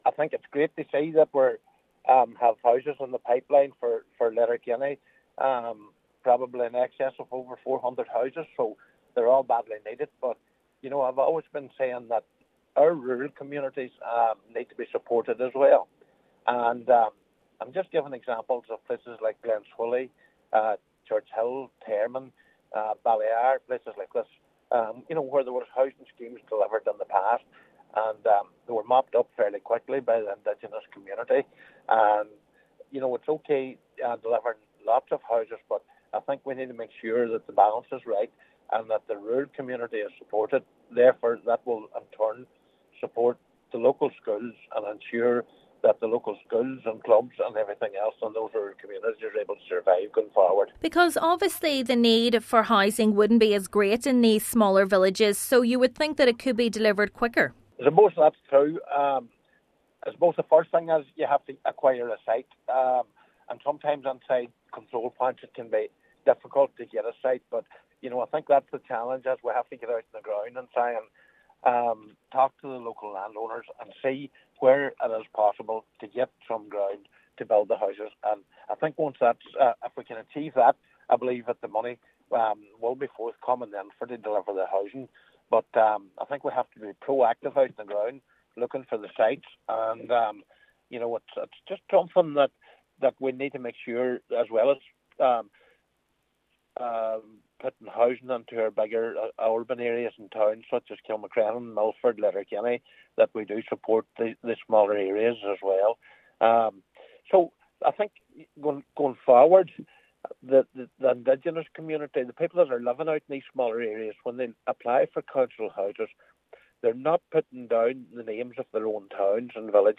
Councillor McBride says a more proactive approach is needed from Donegal County Council: